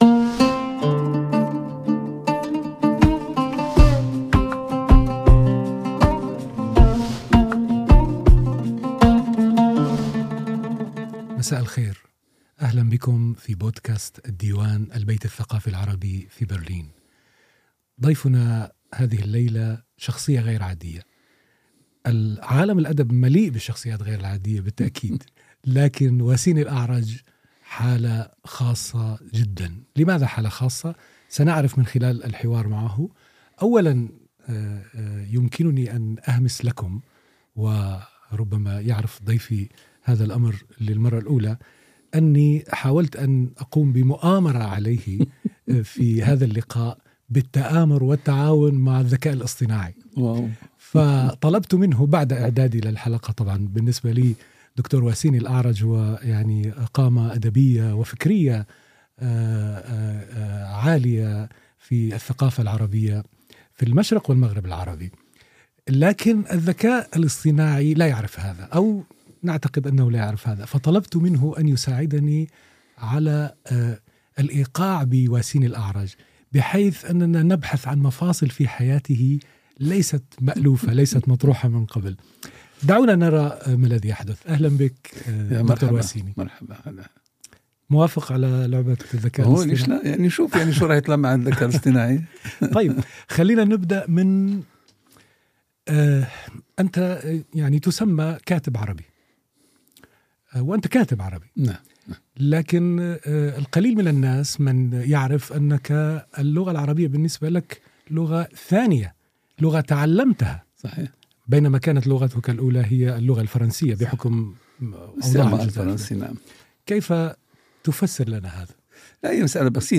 Im Gespräch mit Prof. Dr. Waciny Laredj حوار الديوان مع البروفسور د. واسيني الأعرج ~ DIVAN Podcasts Podcast